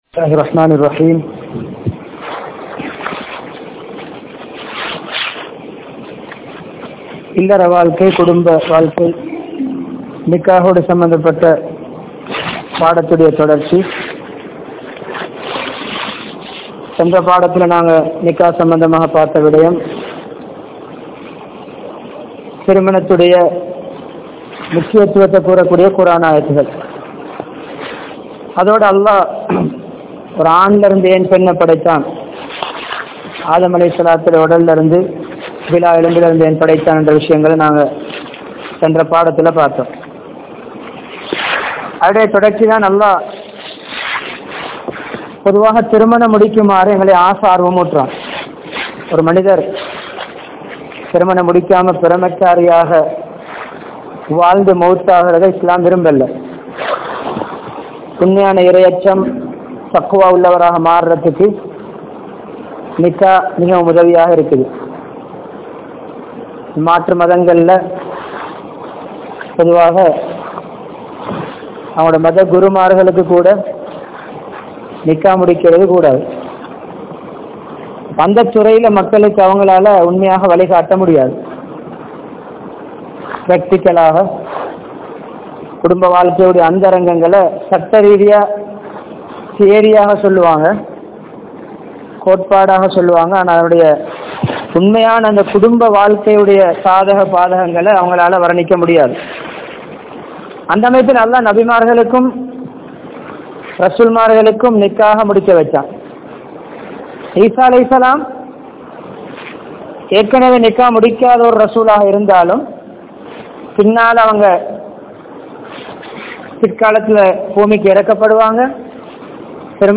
NIkkah Ean Avasiyam? (நிக்காஹ் ஏன் அவசியம்?) | Audio Bayans | All Ceylon Muslim Youth Community | Addalaichenai